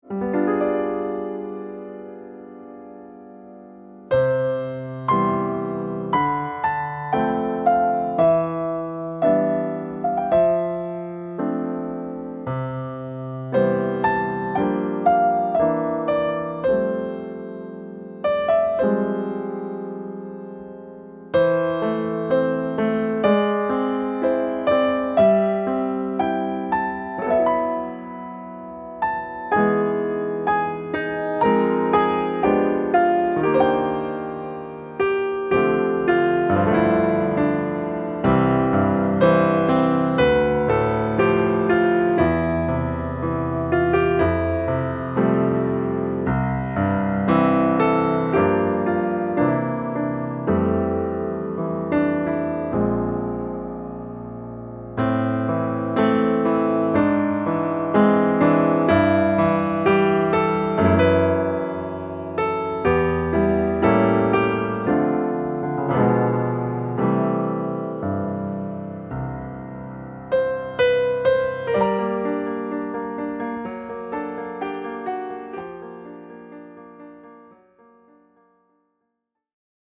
Level : Intermediate | Key : C | Individual PDF : $3.99